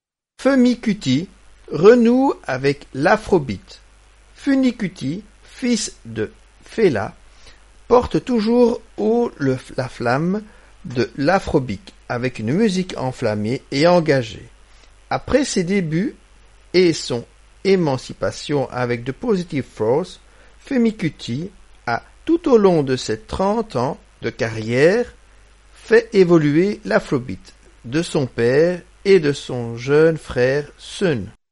enregistré en grande partie à Lagos au Nigeria